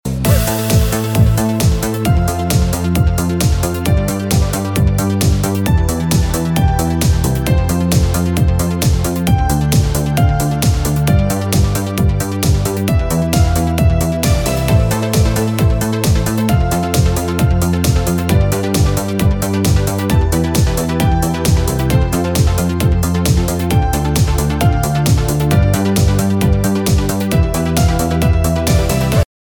• Категория: Ретро рингтоны